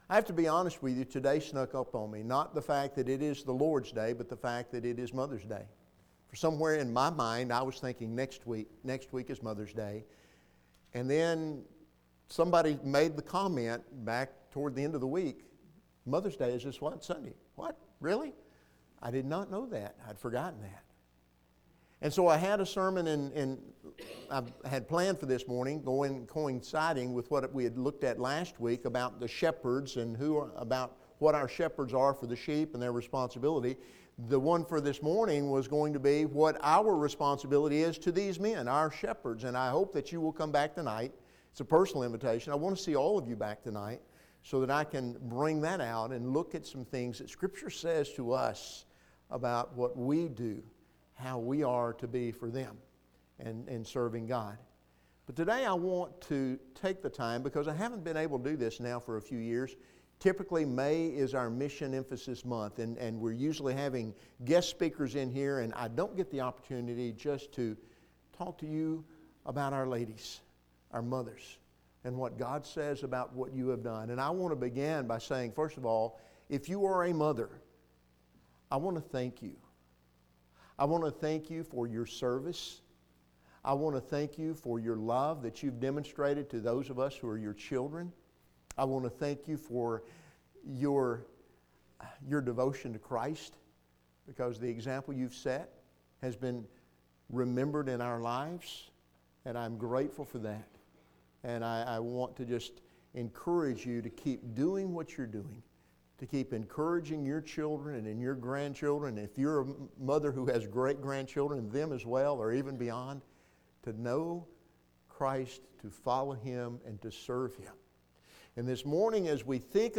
ACTS 6:1-7 Service Type: Sunday Evening